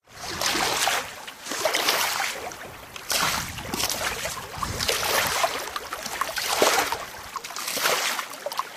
water_swimming_splashing.ogg